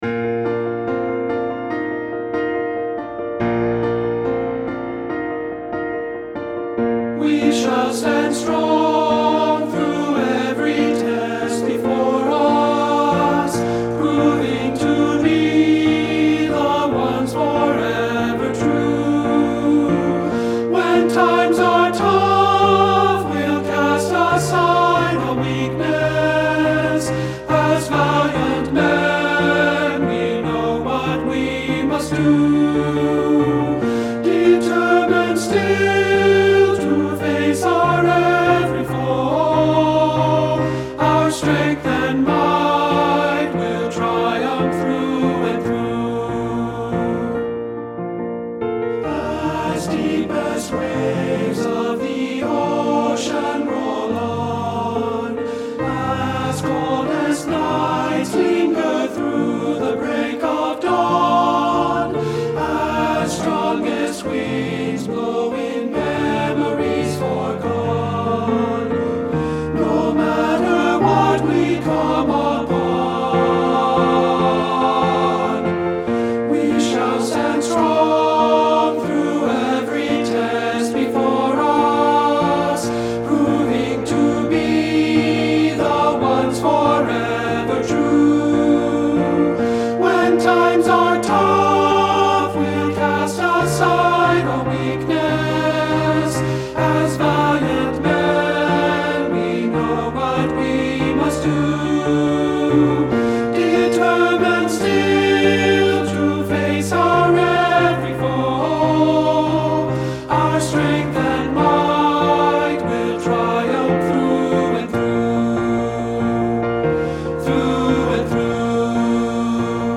Two-Part Unchanged Tenor Voices with Piano
• Piano
Ensemble: Tenor-Bass Chorus
Accompanied: Accompanied Chorus